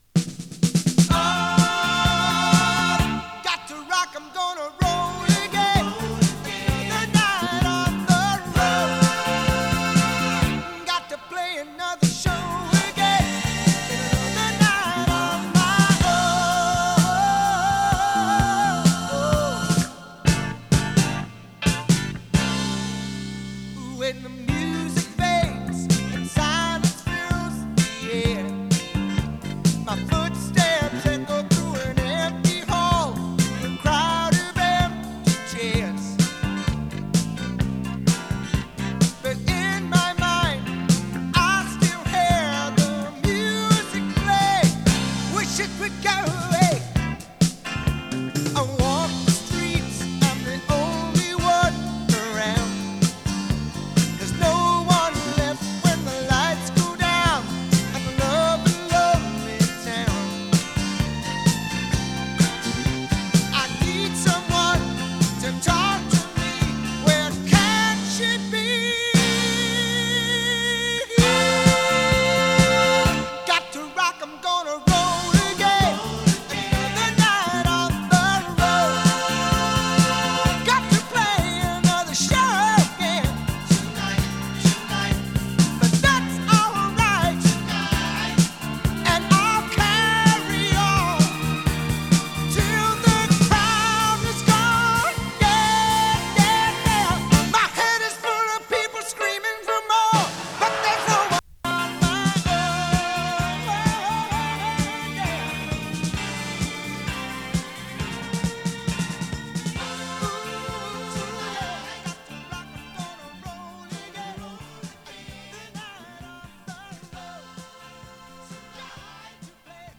ブル－アイドソウル
凝りまくった展開がドラマチックな
＊音の薄い部分で時折パチ・ノイズ。